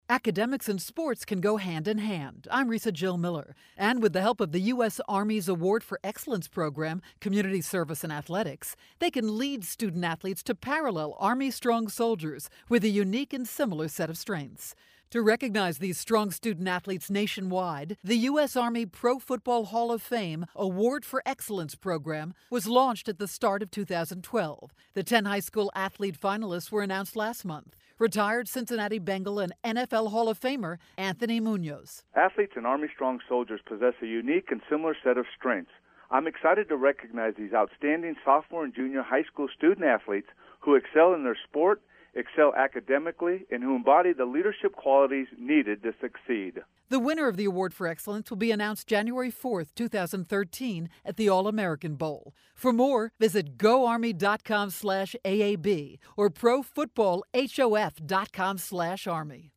October 1, 2012Posted in: Audio News Release